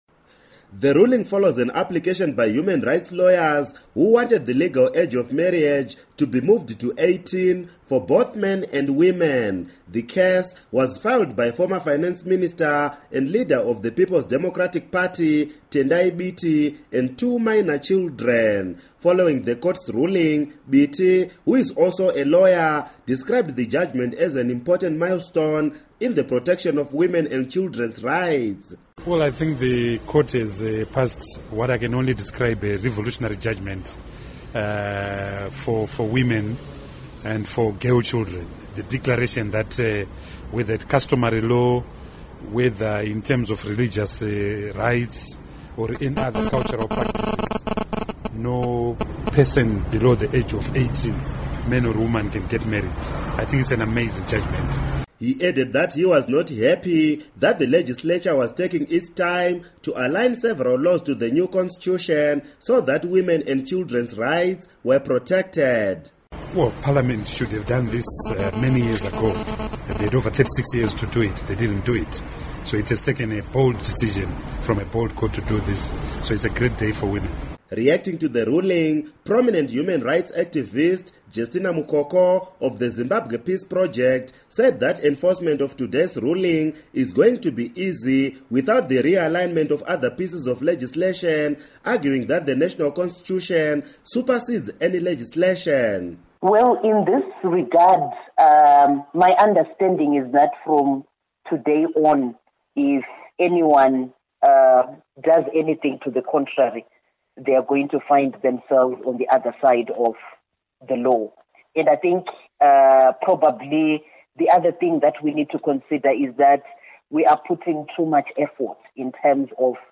Report on Marriages